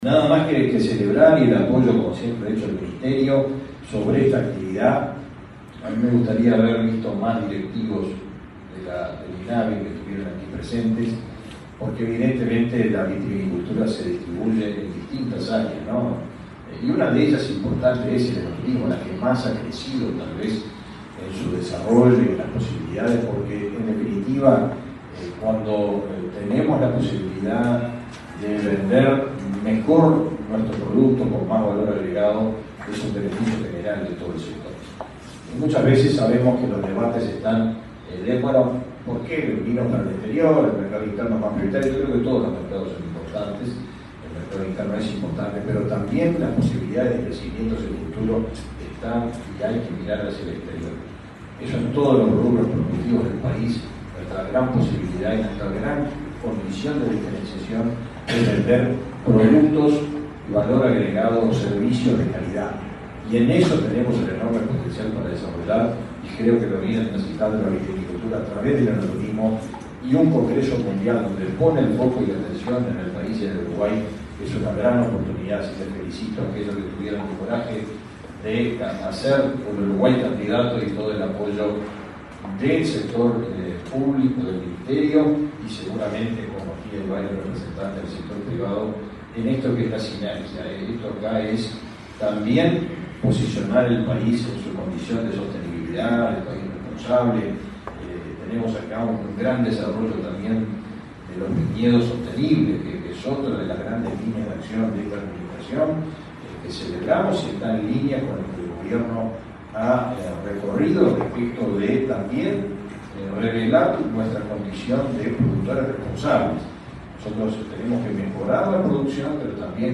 Palabras del ministro de Ganadería, Fernando Mattos
El ministro de Ganadería, Fernando Mattos, participó de la primera Cumbre Global de Enoturismo Responsable, realizado por el Instituto Nacional de